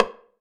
9RIMSH.wav